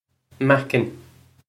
Listen to the pronunciation here. This comes straight from our Bitesize Irish online course of Bitesize lessons.